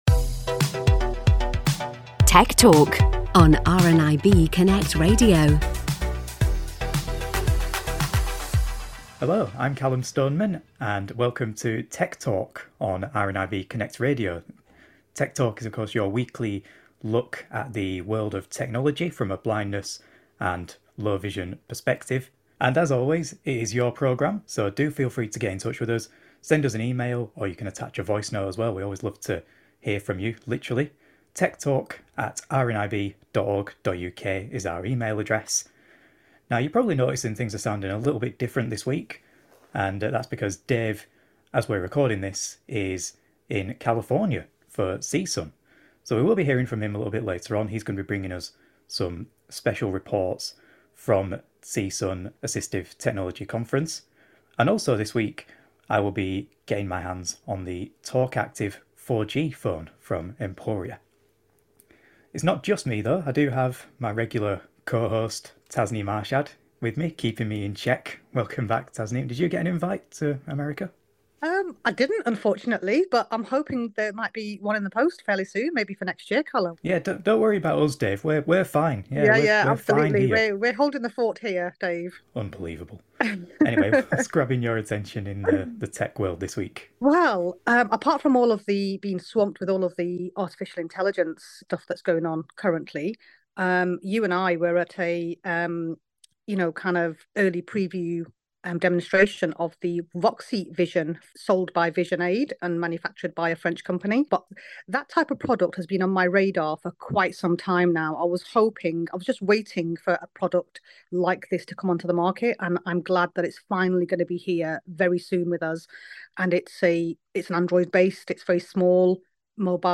Tech Talk 452: CSUN Interviews, Emporia Talk Active